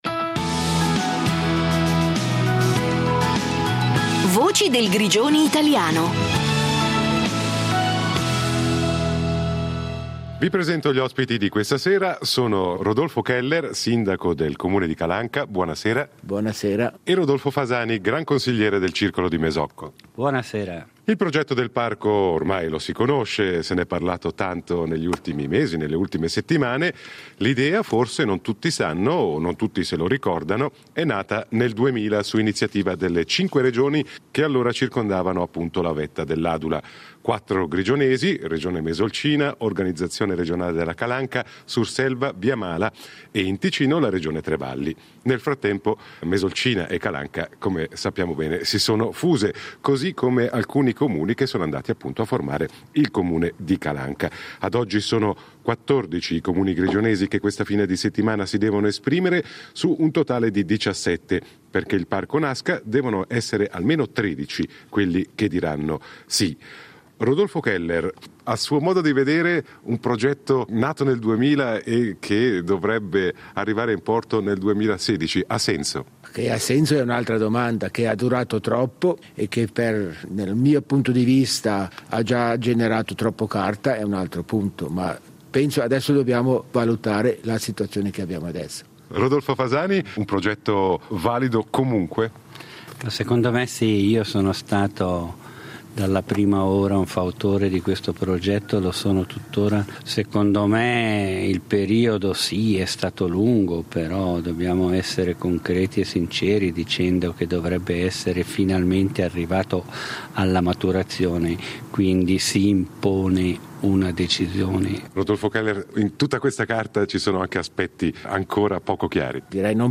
Dibattito moesano sulla creazione del nuovo parco nazionale